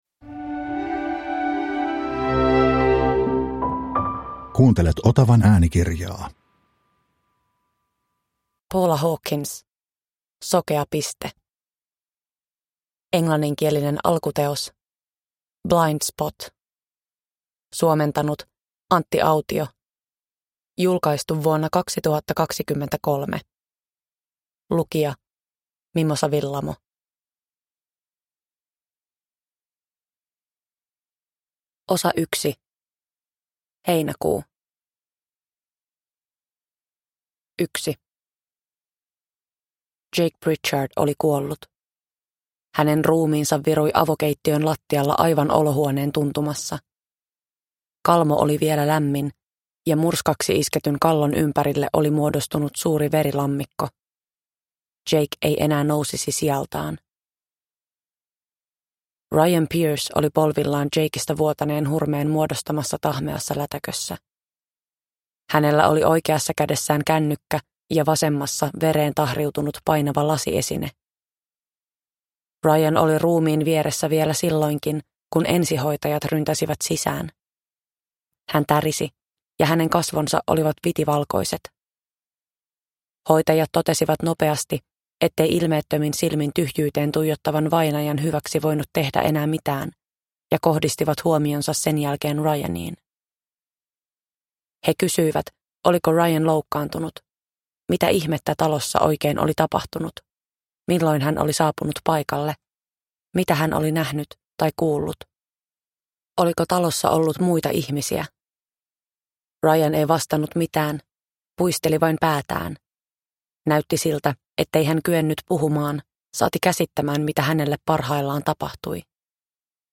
Sokea piste – Ljudbok – Laddas ner
Uppläsare: Mimosa Willamo